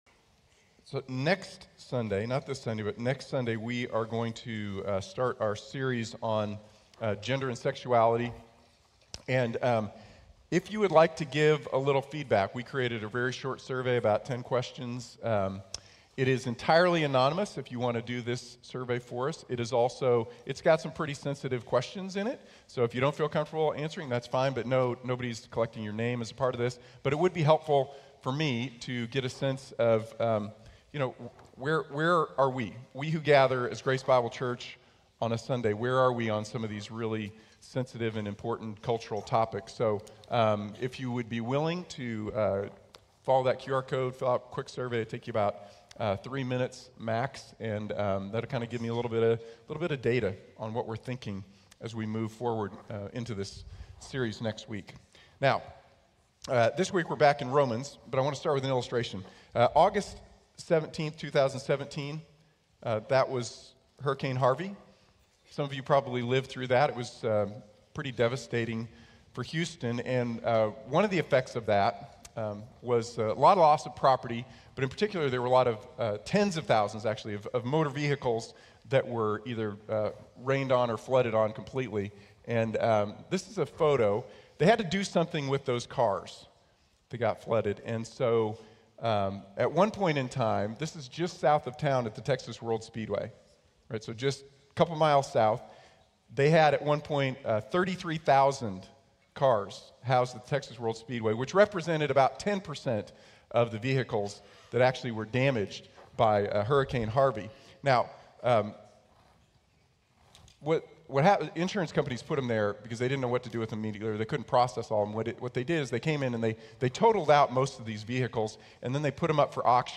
Healthy Churches Win | Sermon | Grace Bible Church